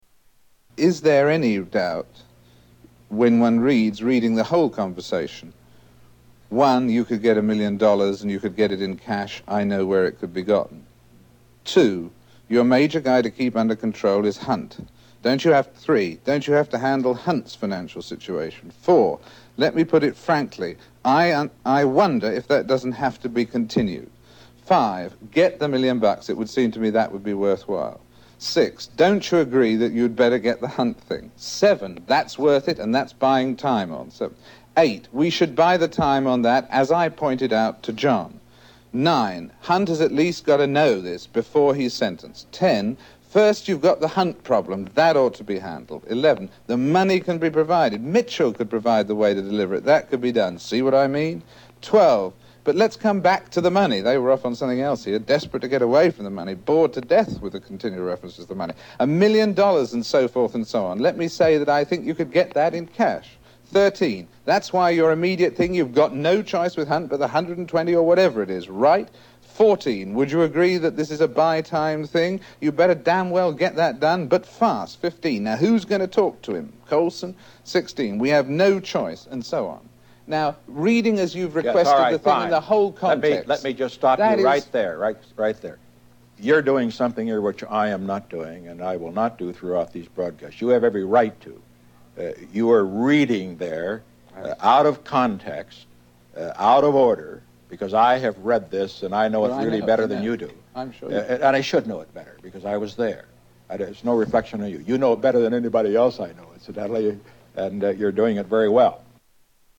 Tags: Historical Frost Nixon Interview Audio David Frost Interviews Richard Nixon Political